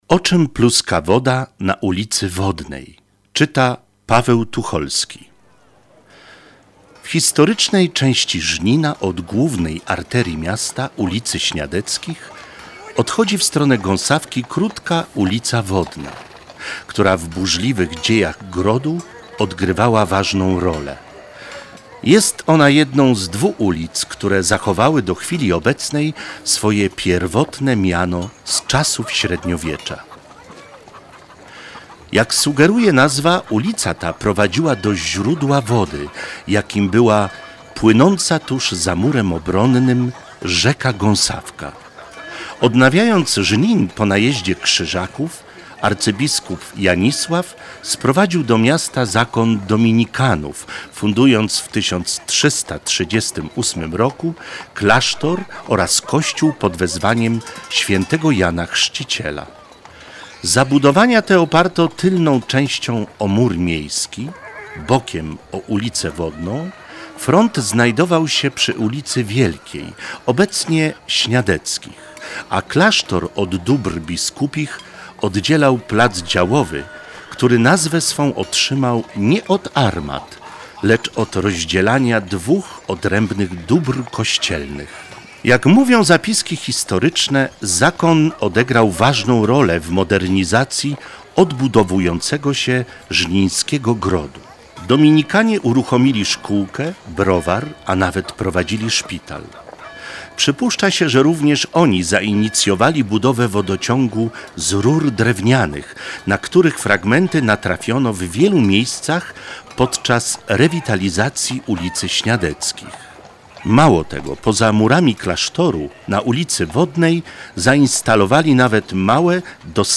Udostępnienie wersji audio legendy "O czym pluska woda na ulicy Wodnej"